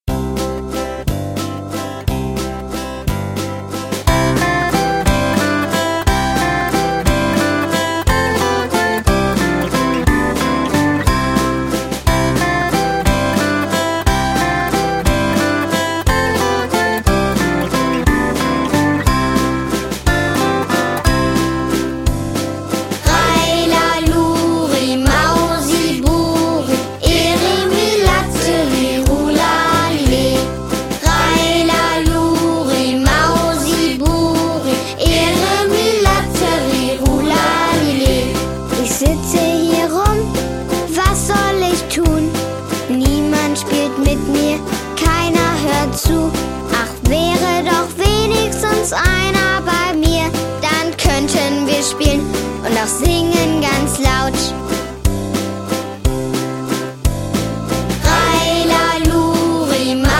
Mal swingend, mal rockig, mal Texte für den Kopf,
mal Rhythmen die in die Beine gehen...